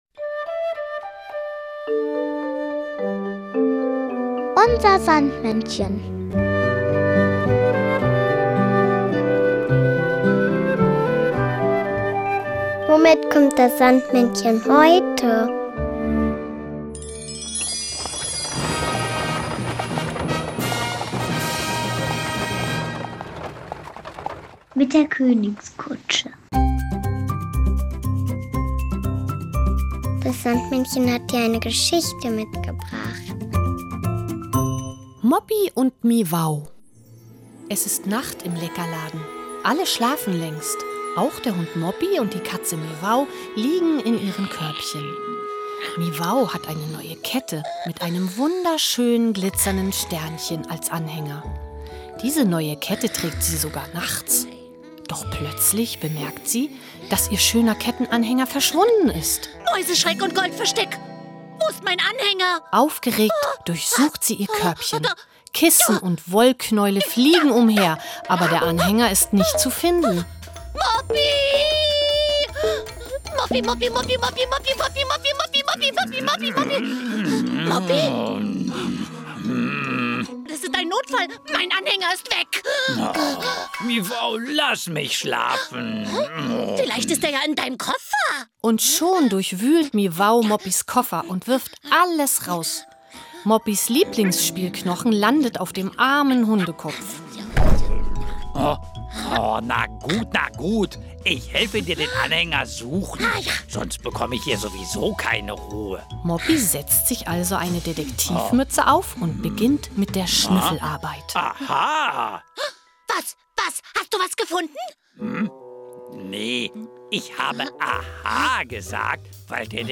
Neben bekannten Figuren wie den Erdmännchen Jan und Henry, Kalli oder Pittiplatsch bietet der Podcast großartige Märchen und lustige Geschichten. Und das Beste: Man hört, mit welchem Fahrzeug das Sandmännchen heute vorbeikommt! UNSER SANDMÄNNCHEN hat aber nicht nur zauberhafte Hörspiele dabei, sondern auch noch ein passendes Lied und den berühmten Traumsand.